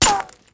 auto_hit_stone2.wav